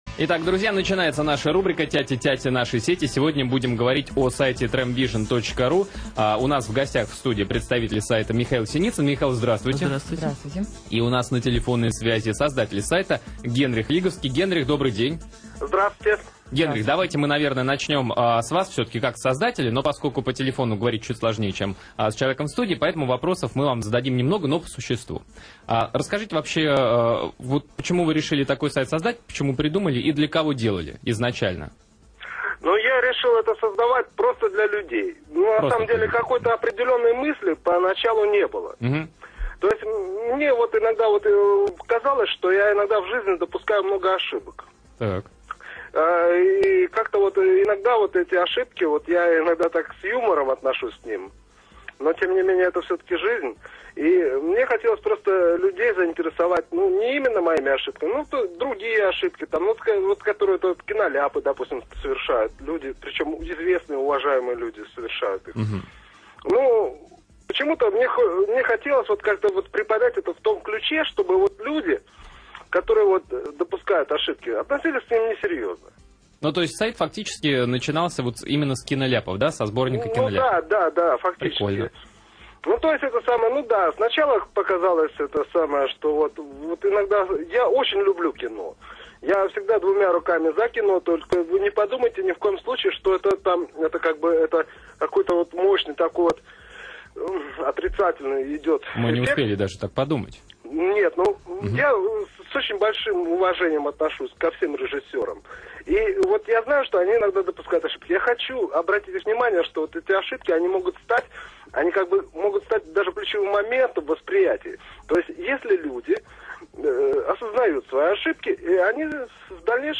Интервью для радиостанции "МАЯК"
Отрубить сайт в прямом эфире!
Радио «Маяк» ведет передачи в прямом эфире без обмана.